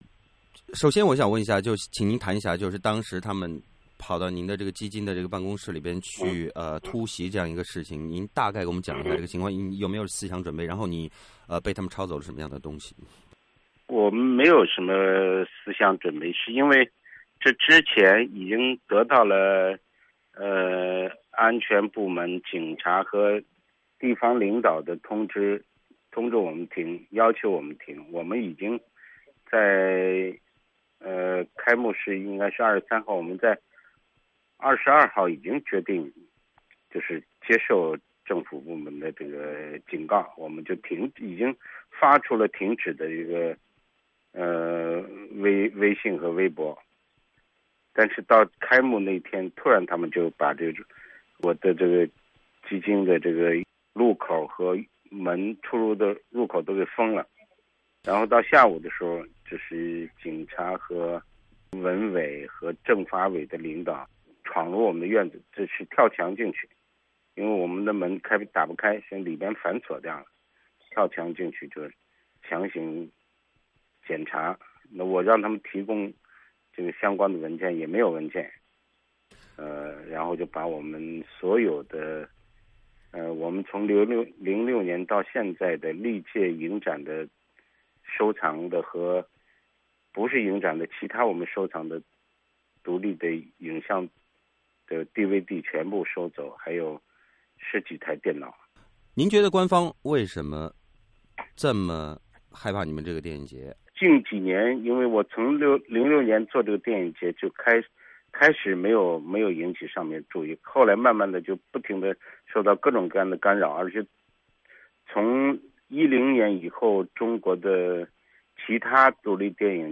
原定于8月23至31日举办的北京宋庄第11届独立影像展在开幕前夕，被北京通州区警方强迫取消。美国之音采访了该独立影展，以及独立电影基金的创始人栗宪庭。